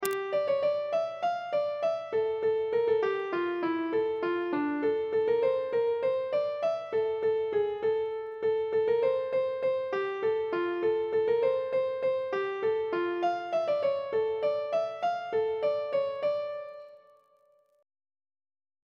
Air.